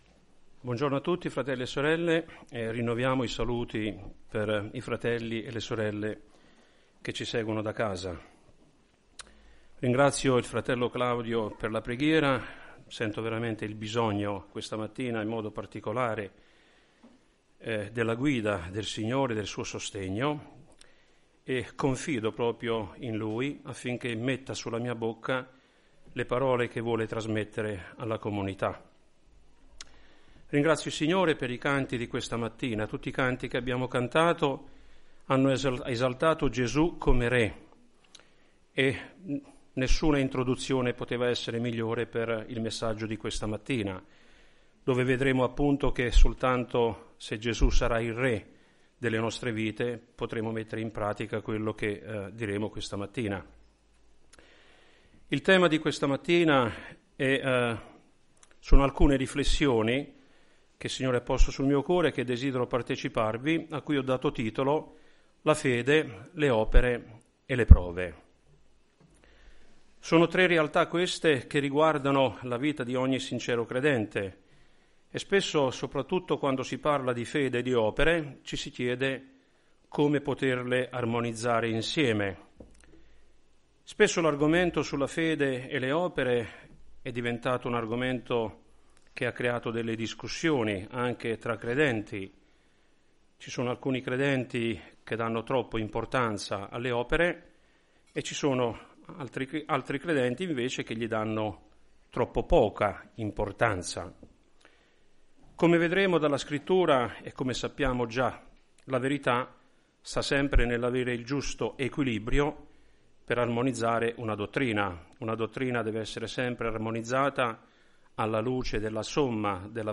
Predicazioni